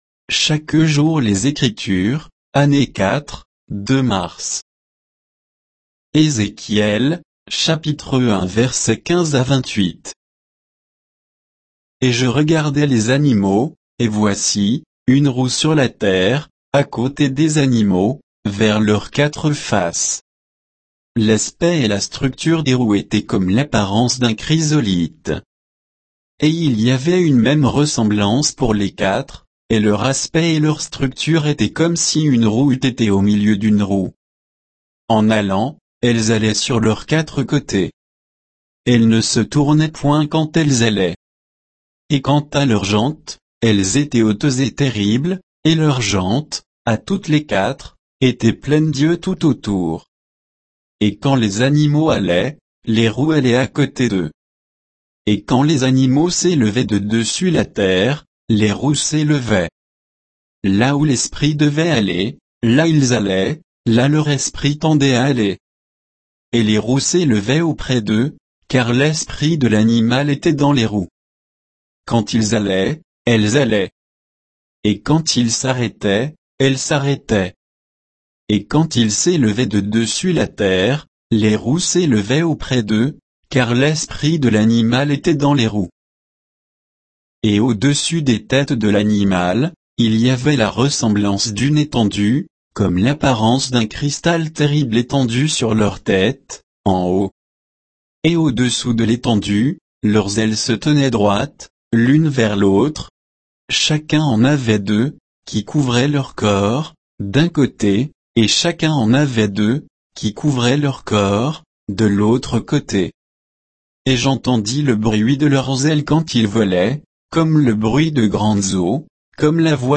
Méditation quoditienne de Chaque jour les Écritures sur Ézéchiel 1, 15 à 28